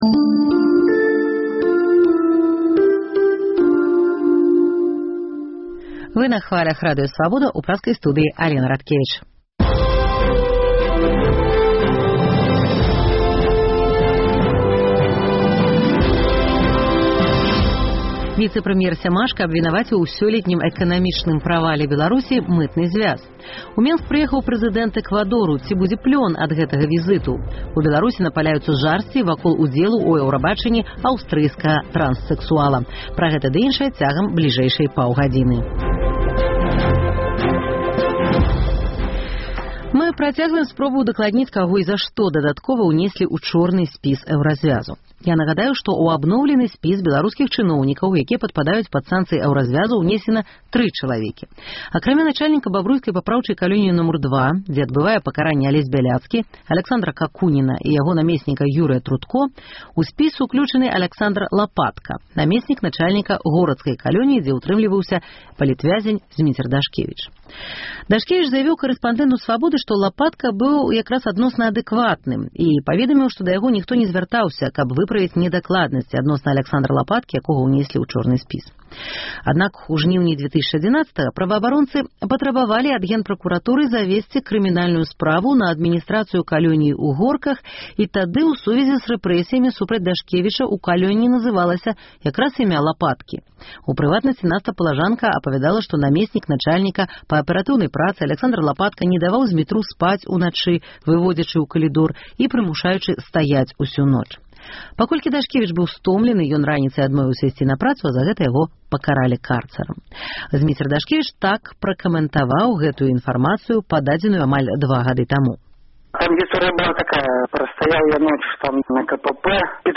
Патэлефануйце ў жывы эфір і падзяліцеся сваёй думкай: Ці падтрымалі б вы ўрадавае рашэньне выйсьці з Мытнага зьвязу і плаціць за нафту і газ па р